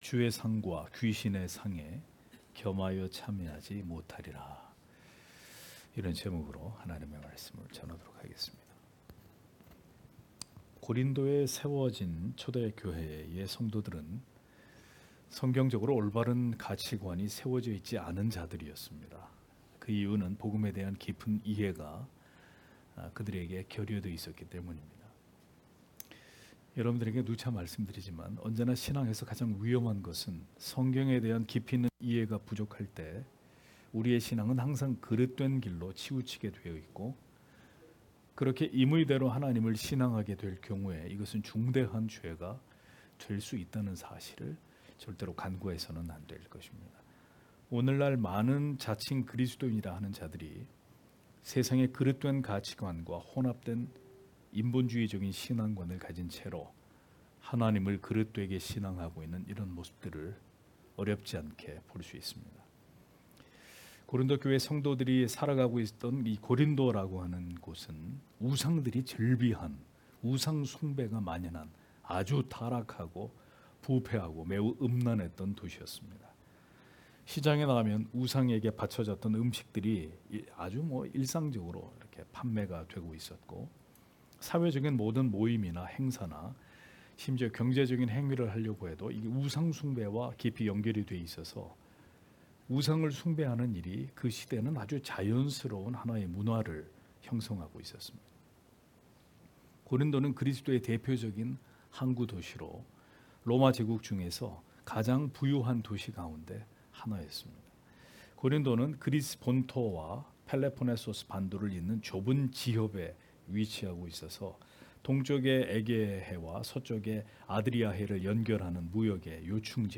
금요기도회 - [성찬 사경회 2] 주의 상과 귀신의 상에 겸하여 참예치 못하리라 (고전10장 20-21절)